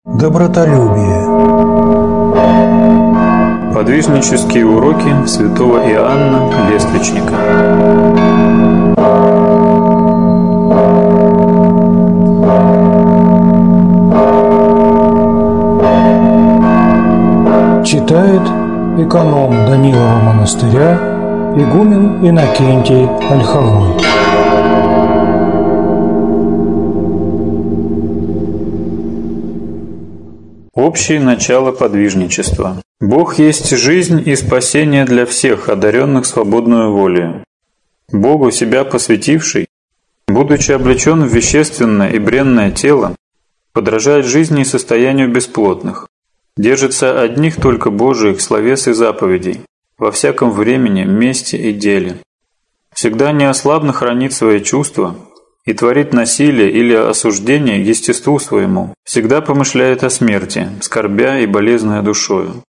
Аудиокнига Святой Иоанн Лествичник | Библиотека аудиокниг